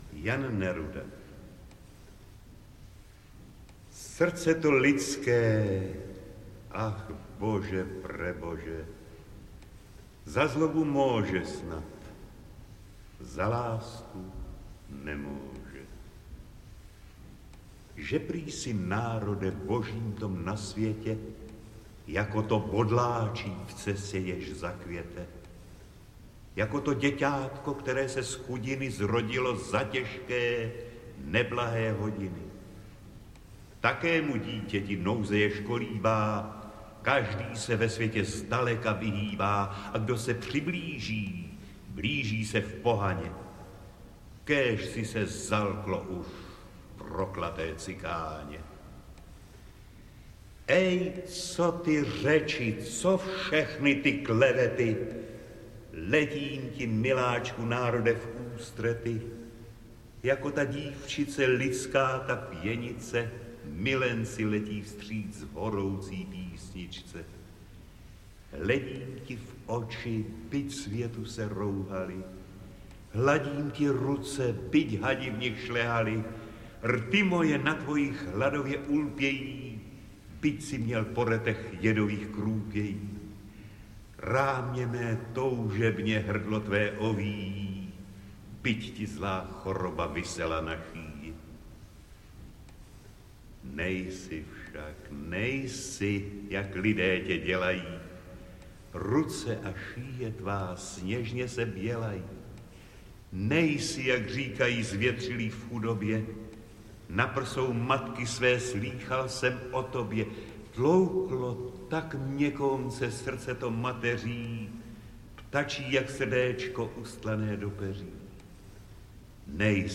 Tato audiokniha přináší záznam slavnostního pořadu z české poezie 19. a 20. století, který uvedla Viola pod názvem Slovo a hlas 22. října 1978 v Dvořákově síni Domu umělců k patnáctému výročí svého vzniku.
Ukázka z knihy
• InterpretOta Sklenčka